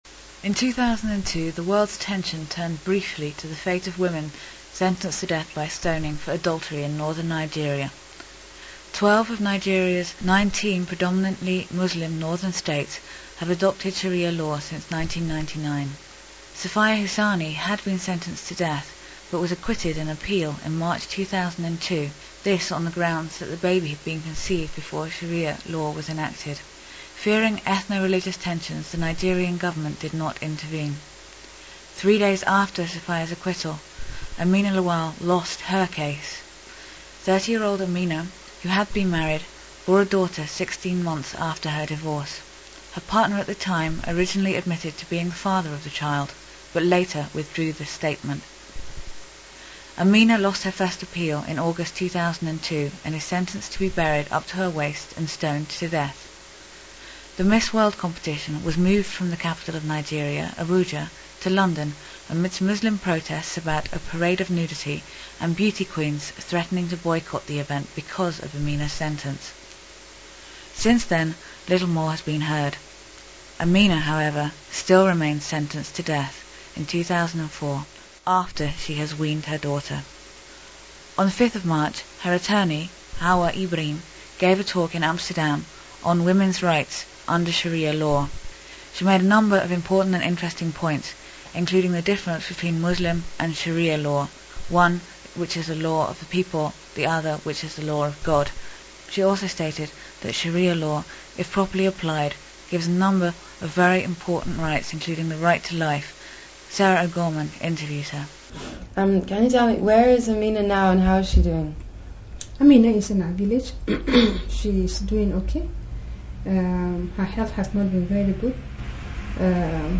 Indymedia NL (Nederland) - Interview with attorney for Amina Lawal